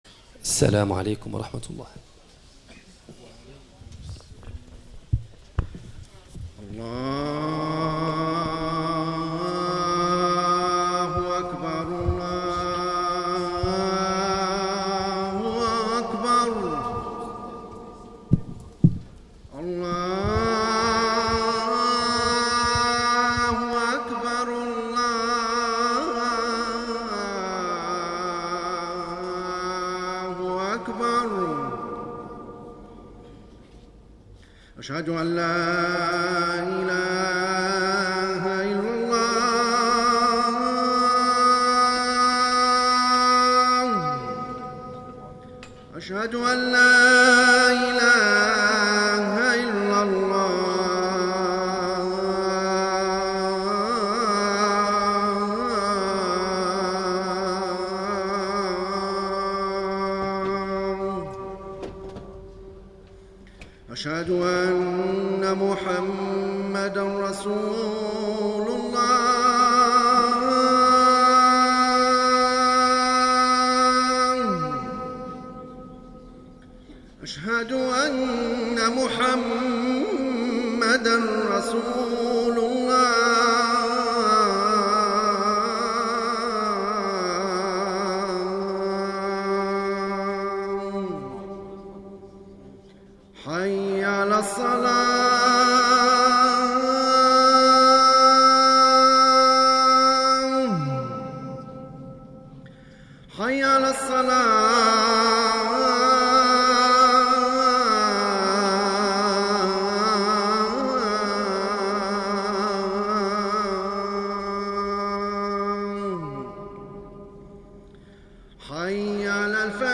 Les prêches du Vendredi